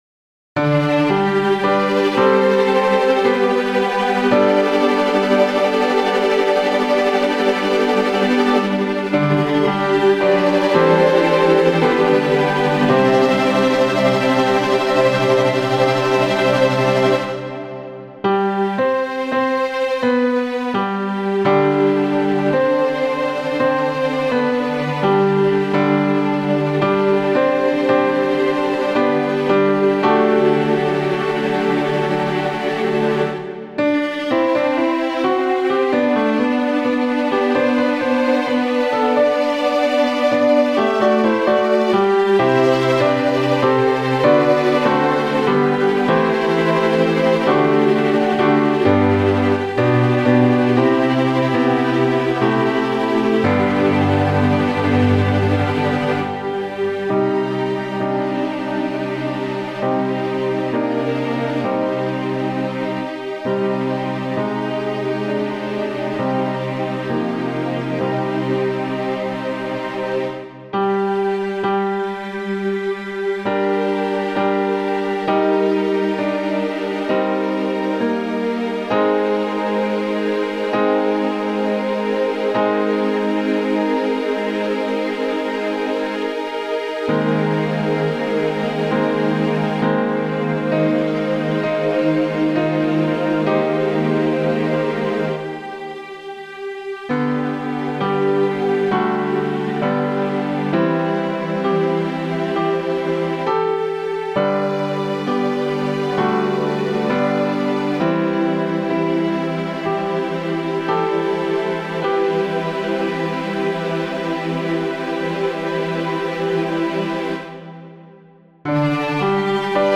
Midi Realization